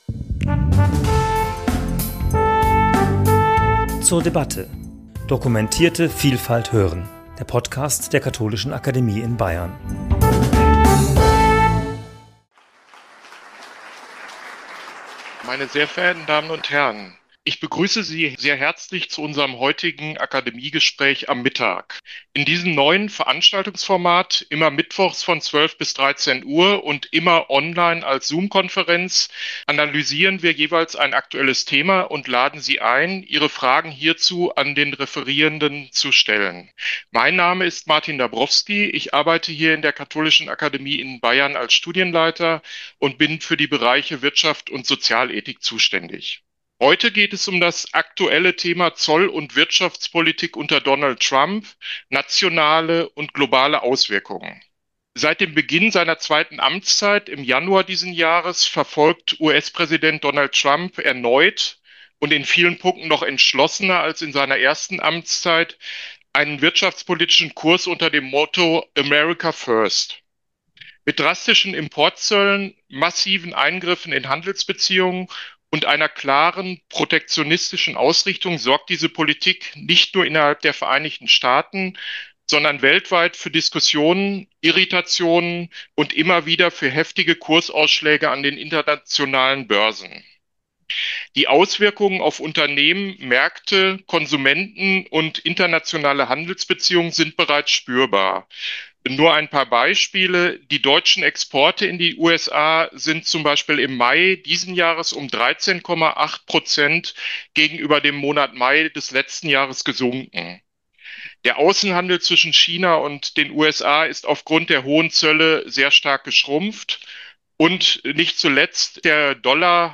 Gespräch zum Thema 'Auswirkungen der Zoll- und Wirtschaftspolitik unter Donald Trump' ~ zur debatte Podcast